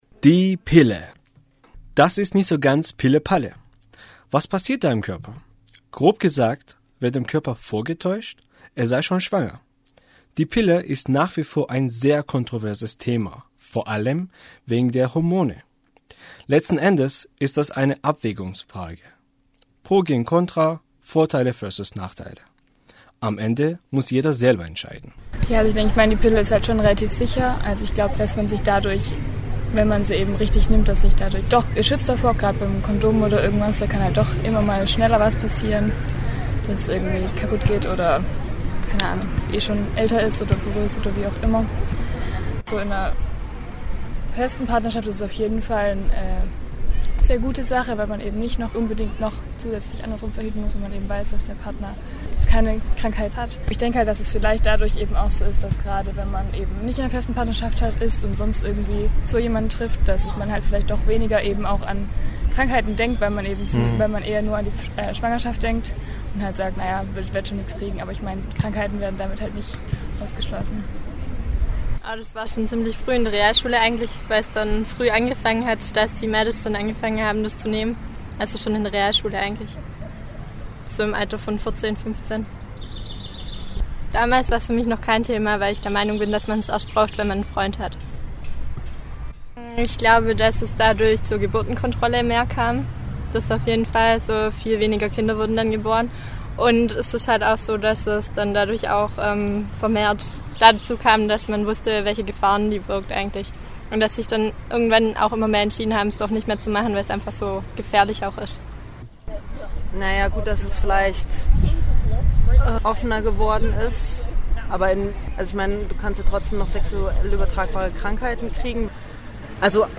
Die Umfrage befasst sich mit der Aufklärung und der Pille. Sie fragt nach dem ersten Moment als man davon hörte bzw. aufgeklärt wurde und die einhergehenden Emotionen. Die Befragten waren weiblich, männlich und in dem Alter von 16 und älter.